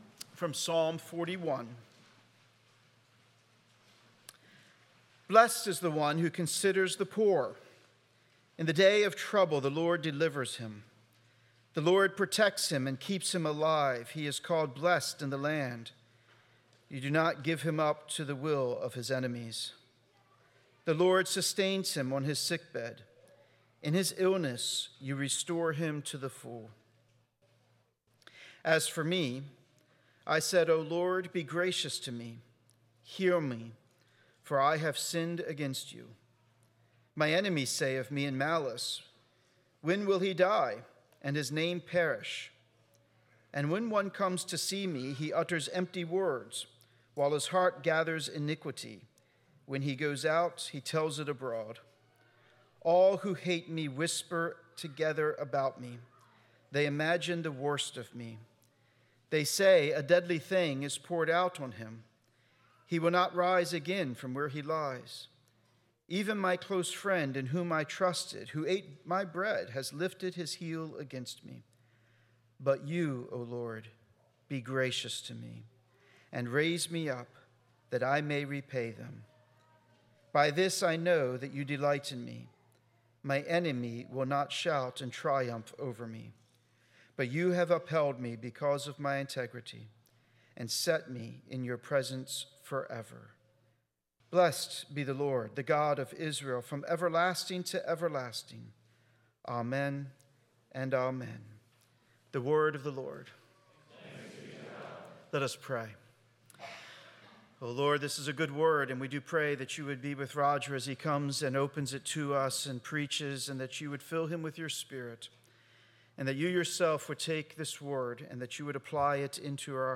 6.22.25 Sermon.m4a